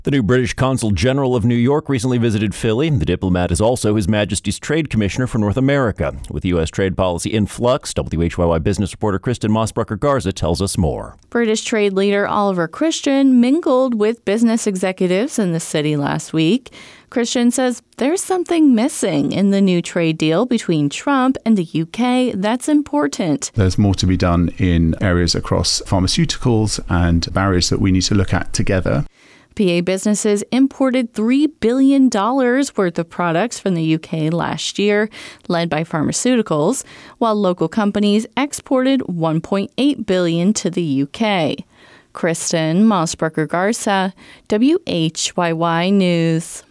Brought to you by Radio Times Radio Times WHYY's Radio Times is an engaging and timely call-in program that tackles wide-ranging issues of concern to listeners in the Delaware Valley.